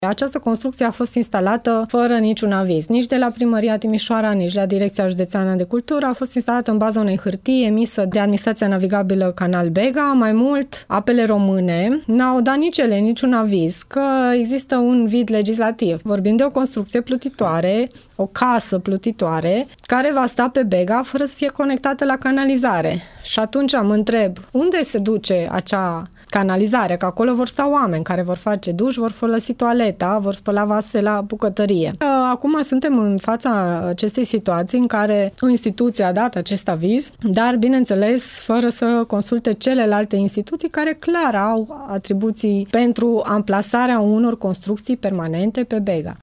Totul se întâmplă în contextul unui vid legislativ în domeniu, a declarat, pentru Radio Timișoara, viceprimarul Paula Romocean.
Paula-Romocean-casa-plutitoare-pe-Bega.mp3